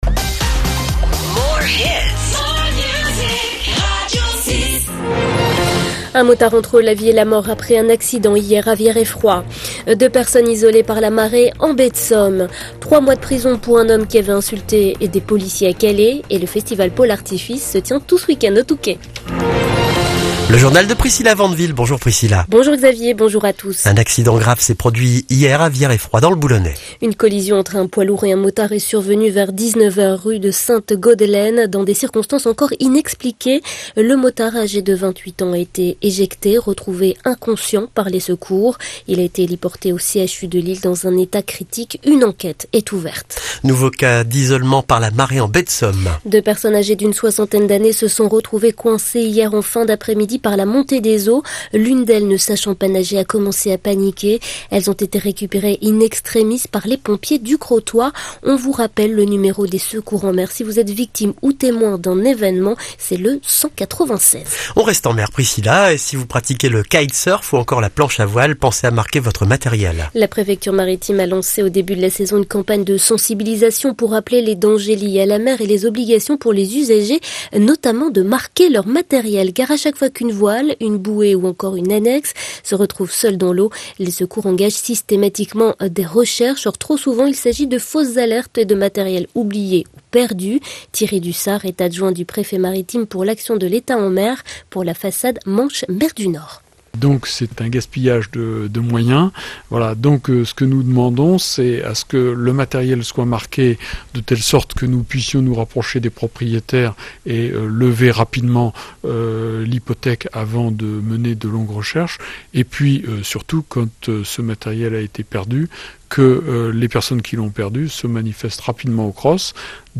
Le journal de 9h du vendredi 12 juillet